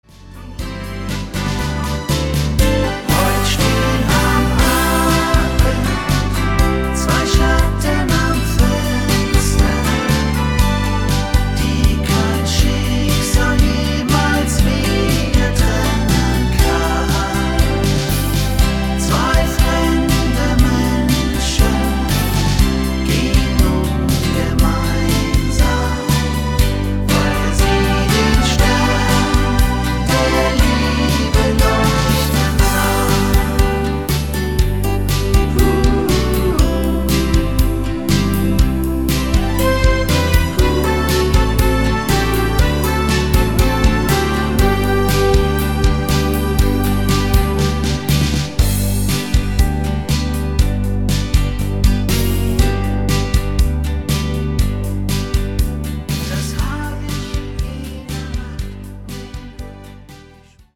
Rhythmus  8 Beat